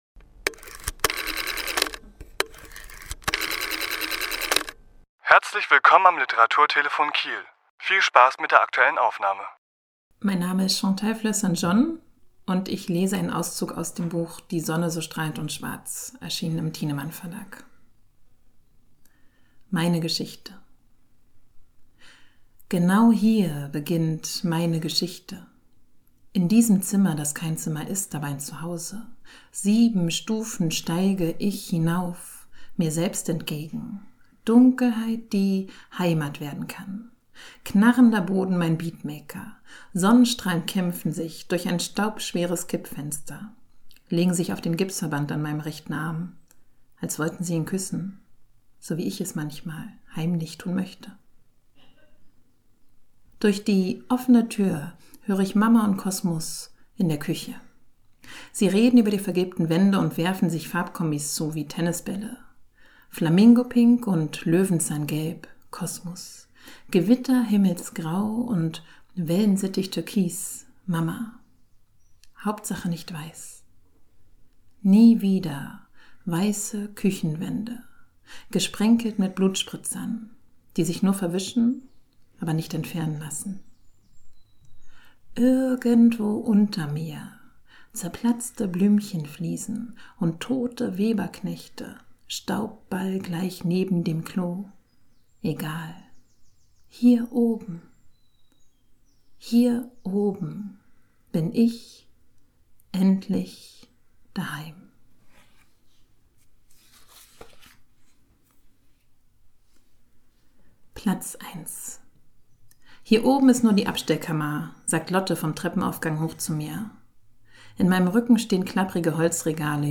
Autor*innen lesen aus ihren Werken
Die Aufnahme entstand anlässlich einer Lesung im Literaturhaus S.-H. am 9. Juli 2024.